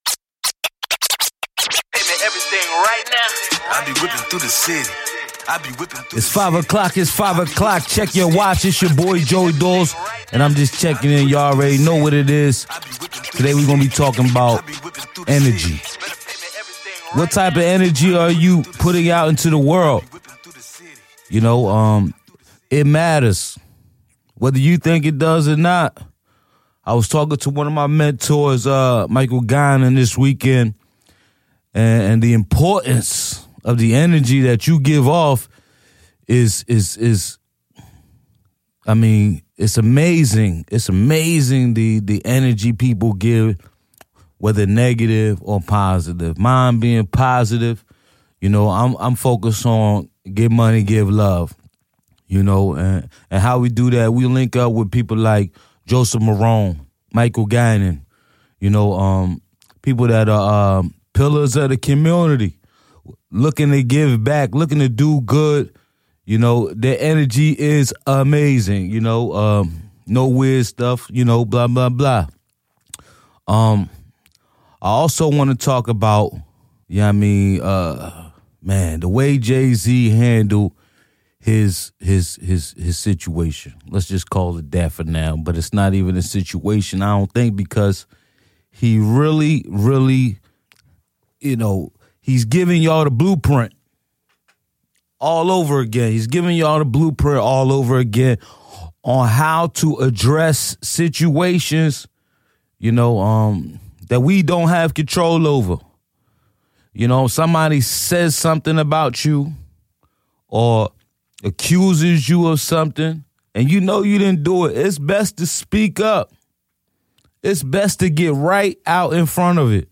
Get Hyped for a half hour of the best rap music